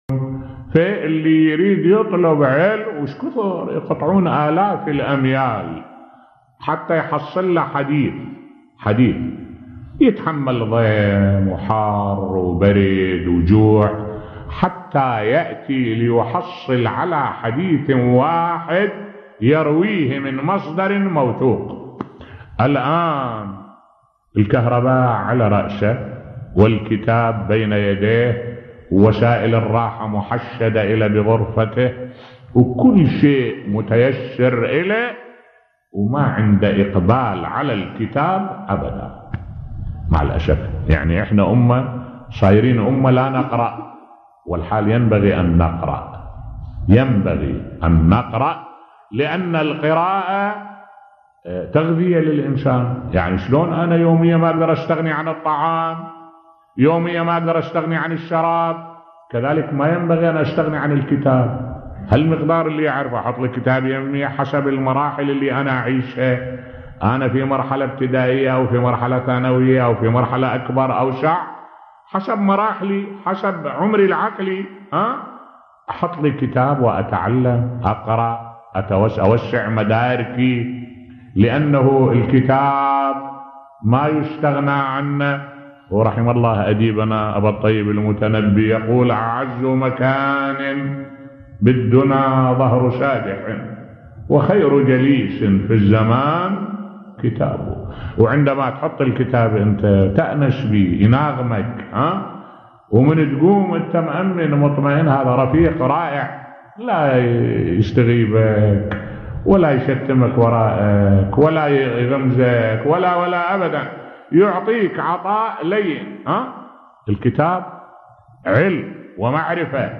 ملف صوتی أهمية القرآءة في حياة الإنسان بصوت الشيخ الدكتور أحمد الوائلي